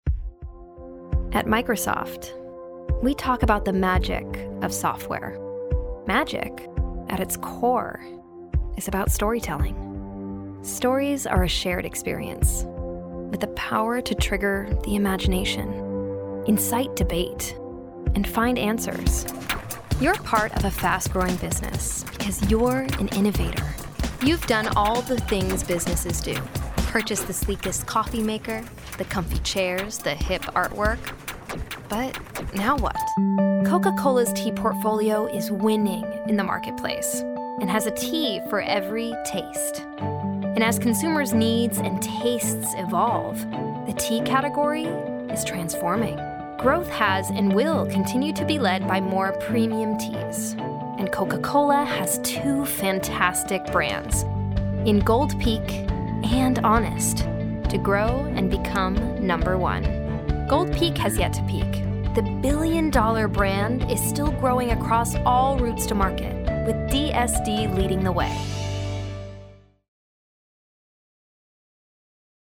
Female Voice Over, Dan Wachs Talent Agency.
Warm, Genuine, Conversational.
Corporate